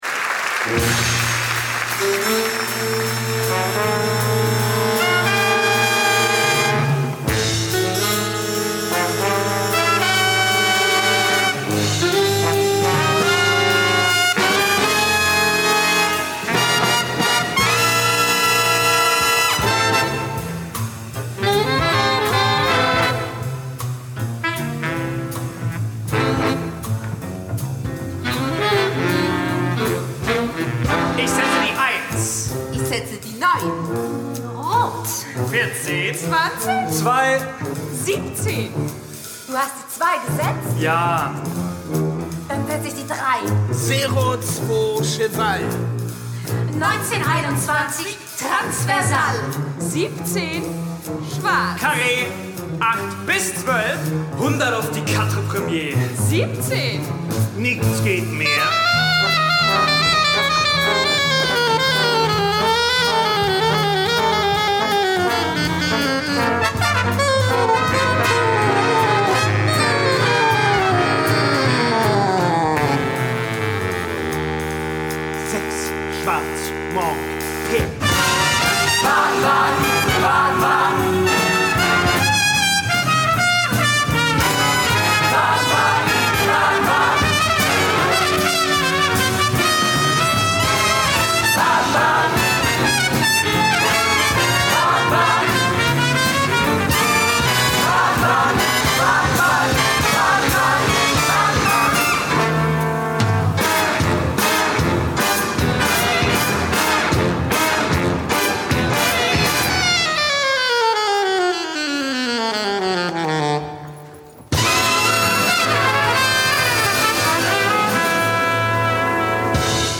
Jazzmusical
komponiert für eine klassische Big Band - Besetzung.
Ein Zusammenschnitt der Musik in 17 Minuten